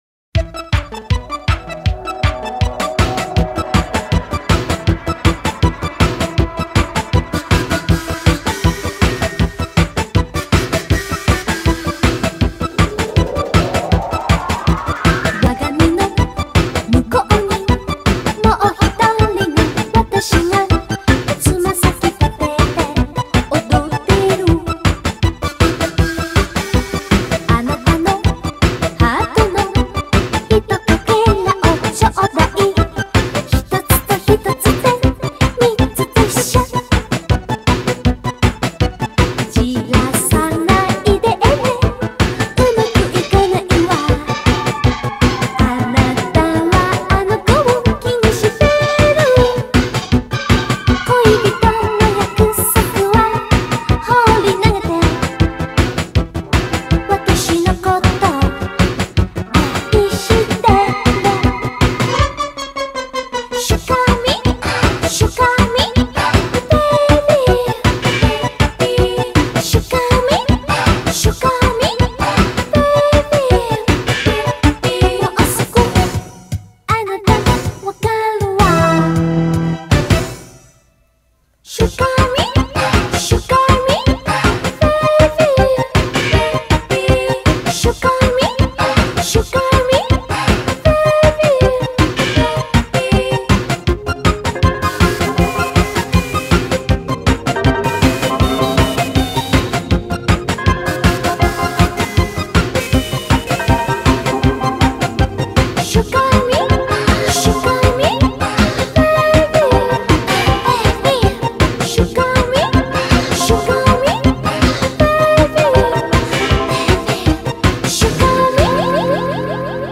BPM159-159
Audio QualityMusic Cut